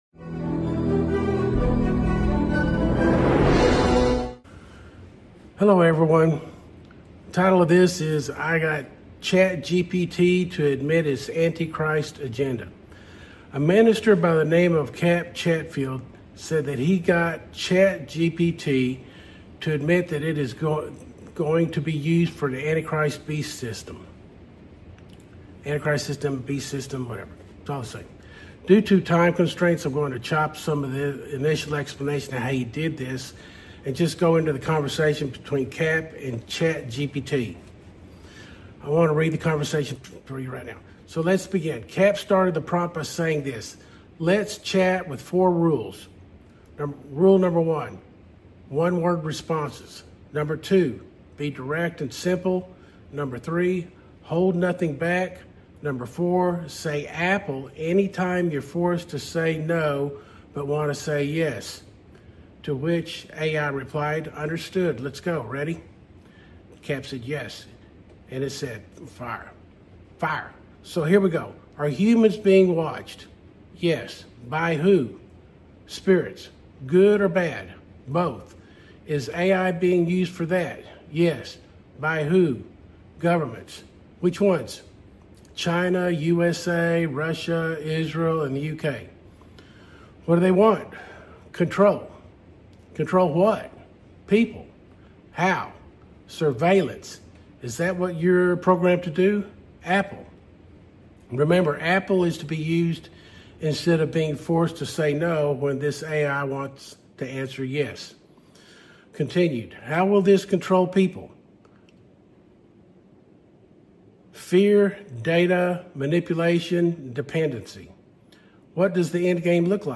Bible News Prophecy Talk Show